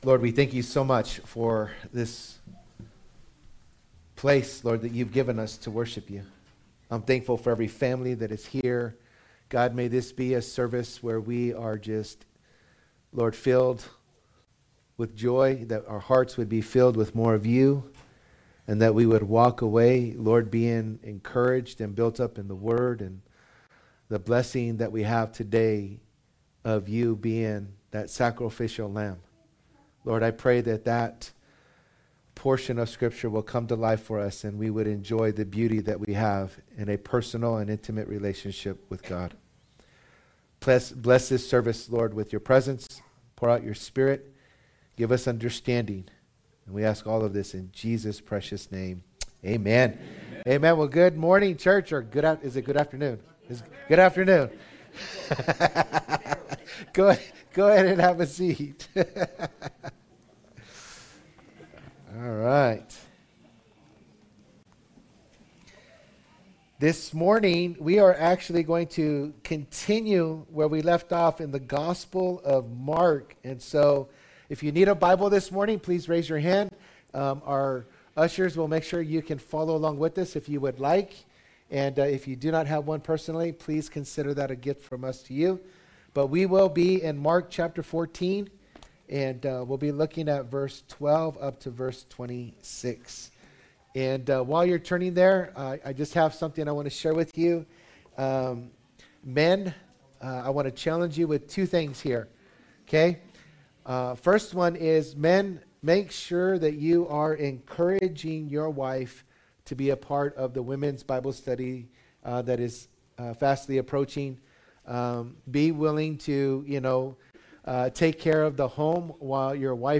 Calvary Chapel Saint George - Sermon Archive